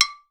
AFRCN AGOGOS